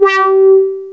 WAH 3.wav